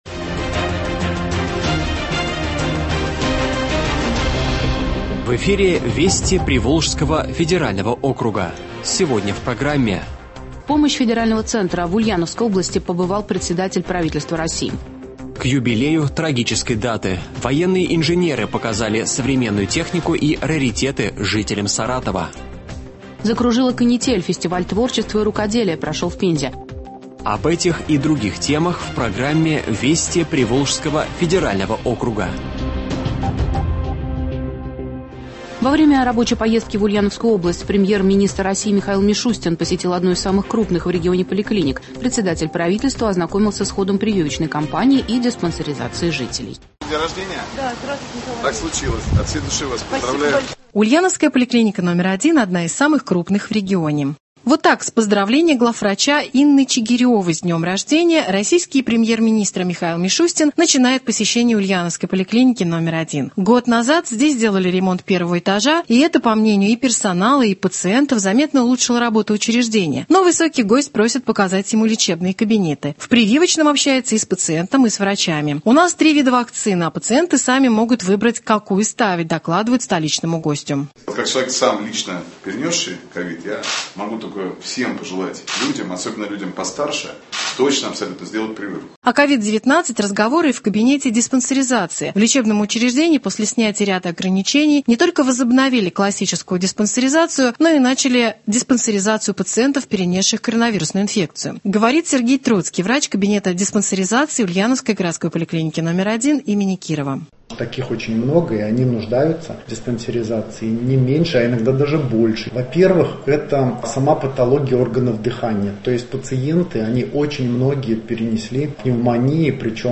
Радиообзор событий в регионах ПВО.